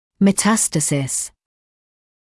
[mə’tæstəsɪs][мэ’тэстэсис]метастаз (мн.ч. metastases [mə’tæstəsiːz])